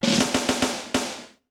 British SKA REGGAE FILL - 02.wav